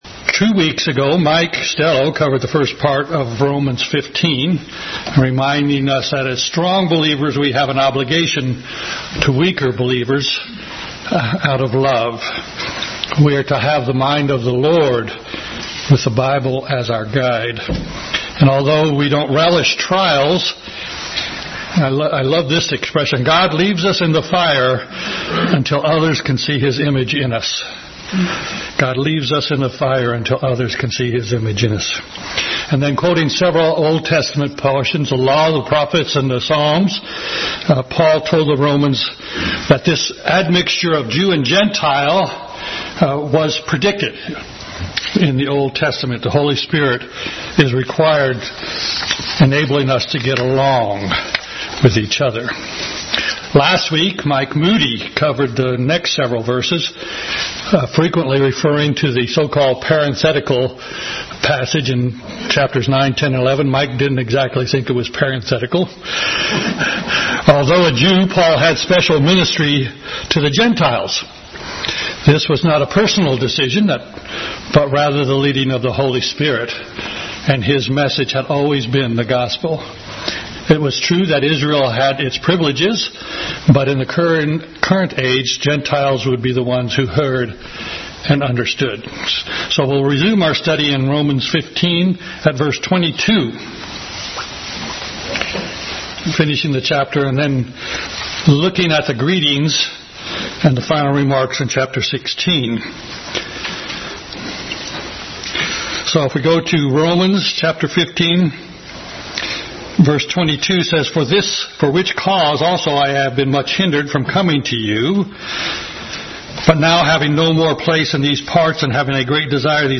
Bible Text: Romans 15:22 – 16:27 | Adult Sunday School. Last of a series of studies in the book of Romans.